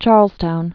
(chärlztoun)